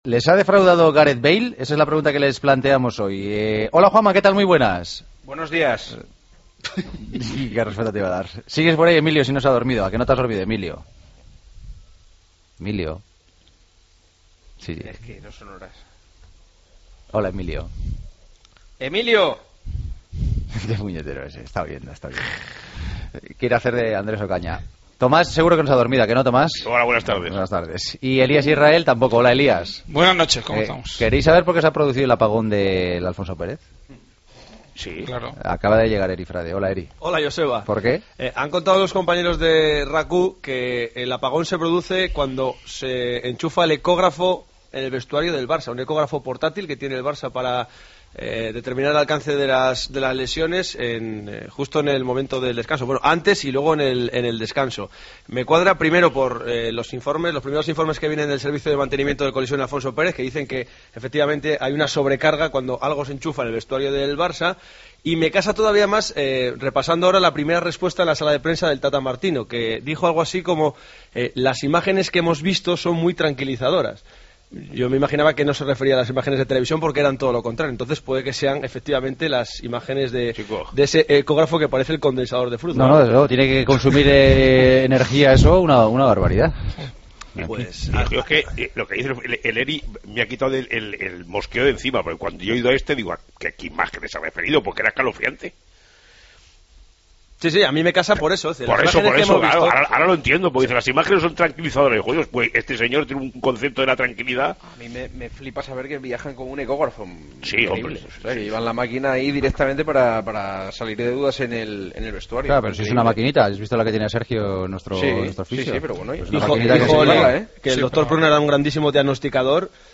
El debate de los jueves: ¿Os está decepcionando Gareth Bale?